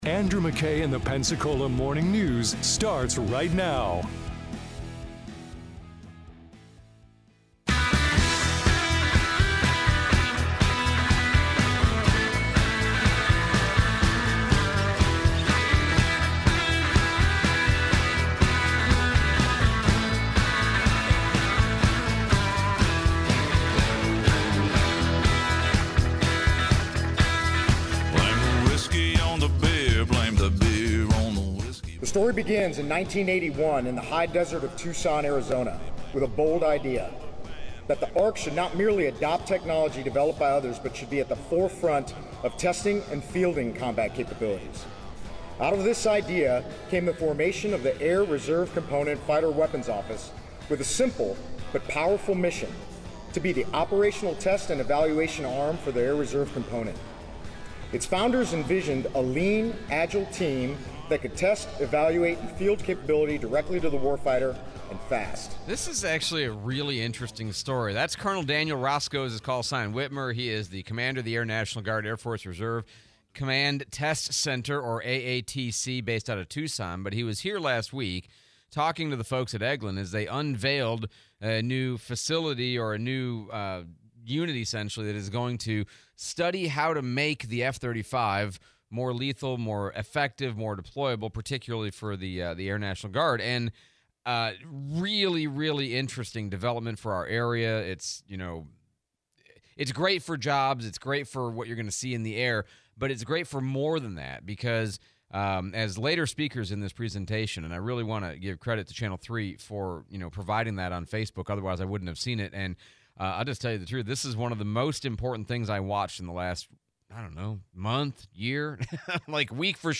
Eglin F-35 test program speech, Replay of Mayor DC Reeves